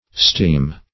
Steem \Steem\ (st[=e]m), v. & n.